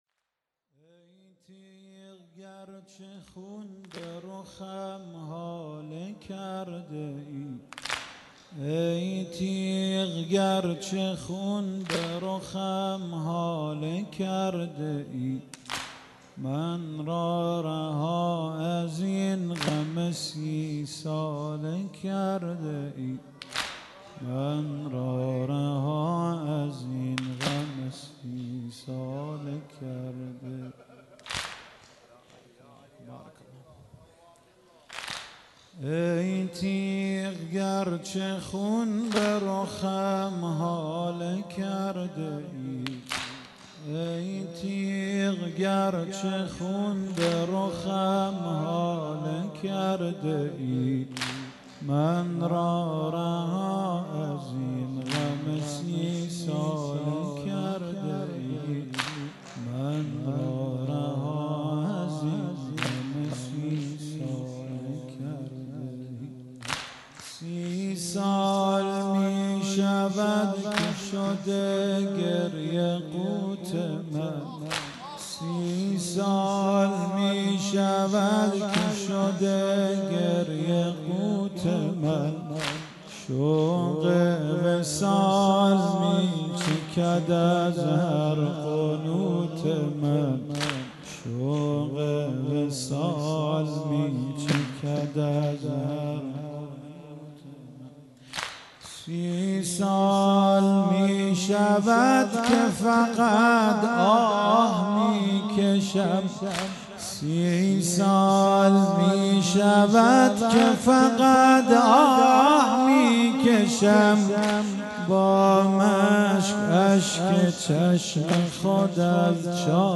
شهادت امیر المومنین (ع)